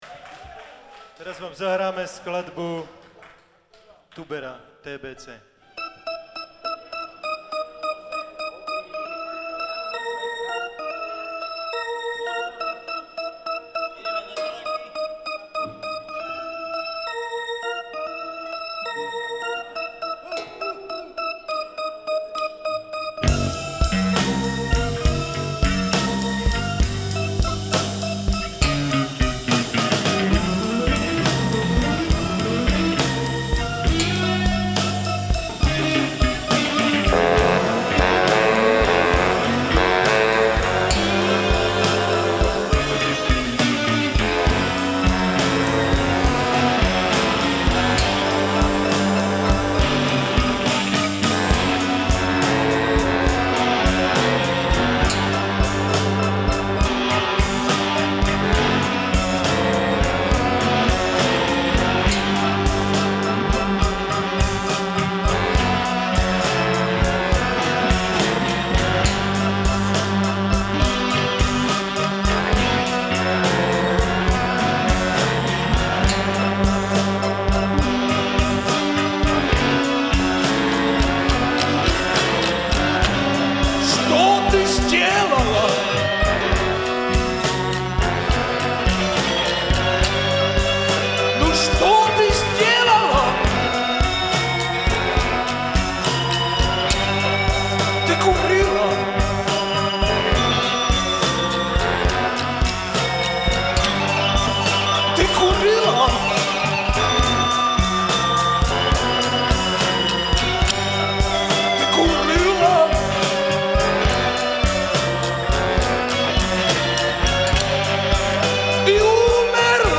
Nahráte: Live Kino Hviezda Trenčín 16.12.2000